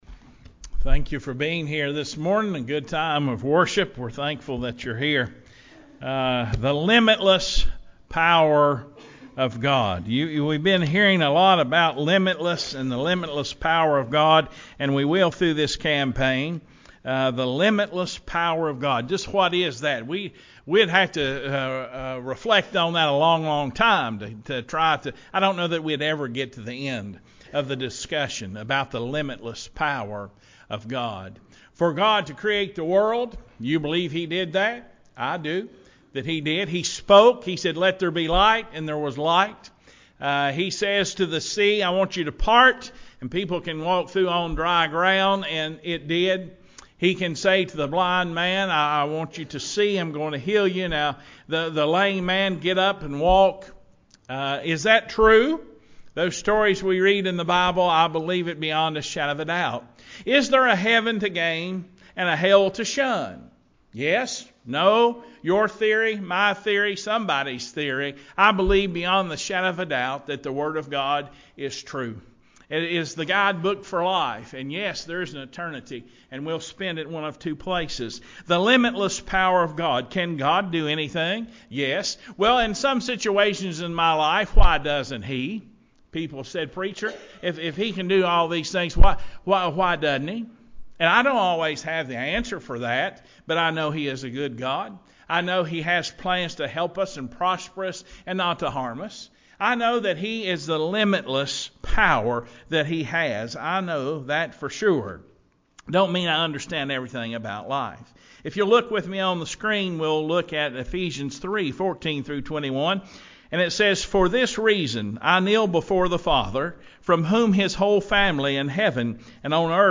041716-sermon-CD.mp3